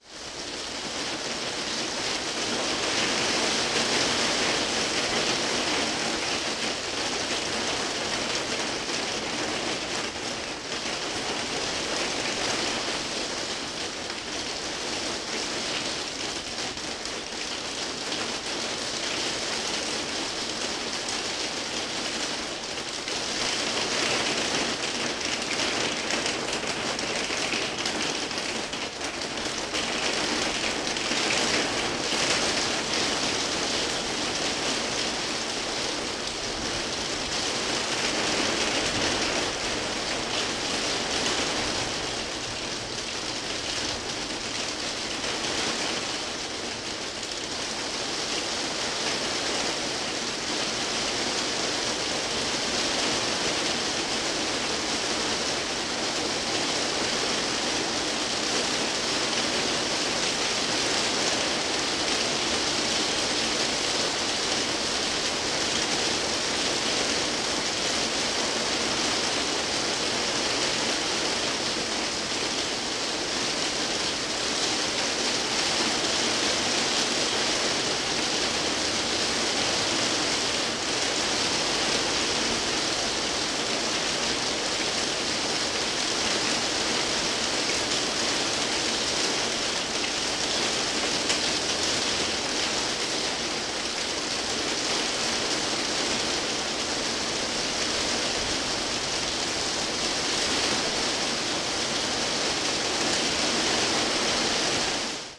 rain_roof.ogg